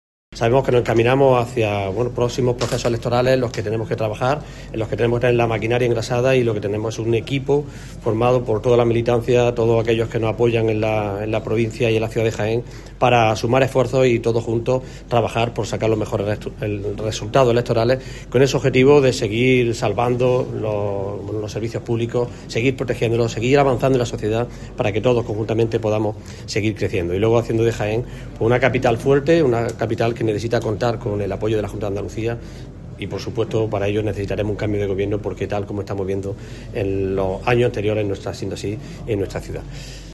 Julio-Millan-asamblea-capital.mp3